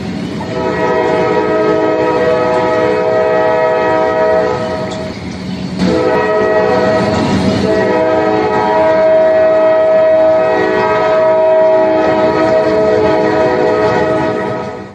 train 4
Tags: project personal sound effects